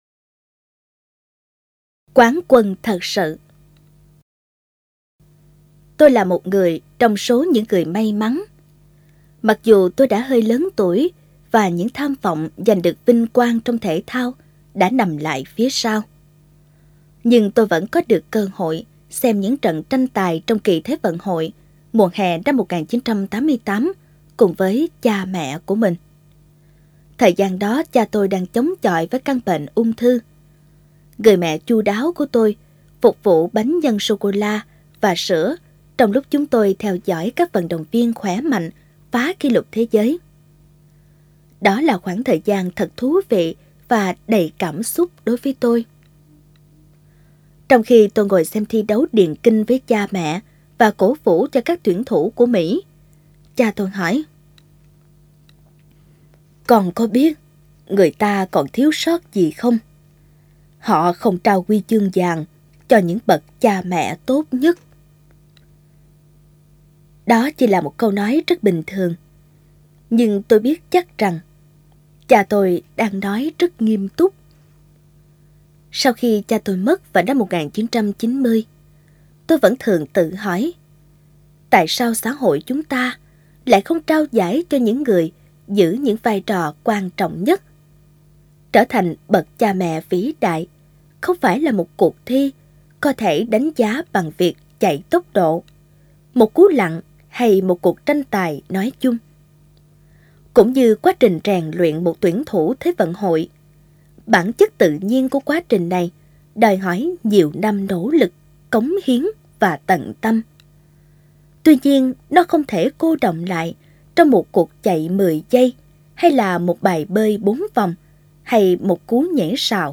Sách nói | Quán quân thật sự